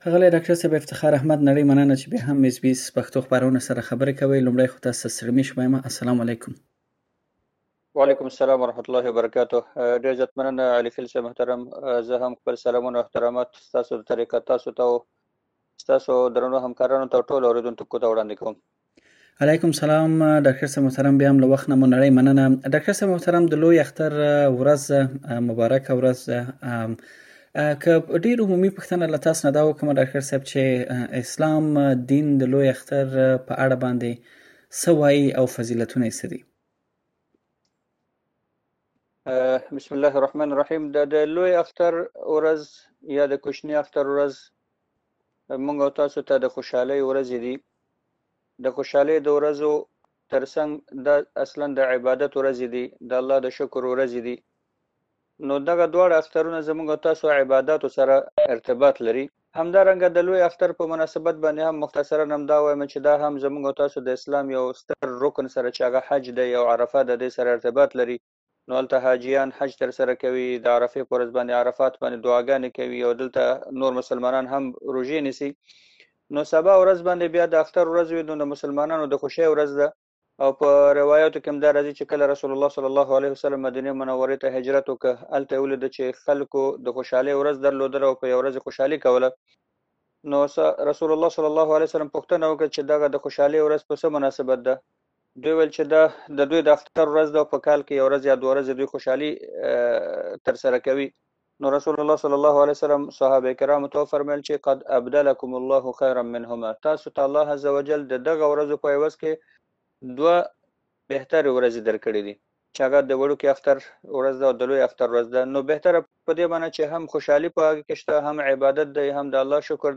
ښاغلي له اس بي اس پښتو خپرونې سره په ځانګړې مرکه کې د لوی اختر پر فضيلتونو او هم په اوسنيو شرايطو کې يو مسلمان څنګه اختر لمانځلی شي پر يادو برخو رڼا اچولې.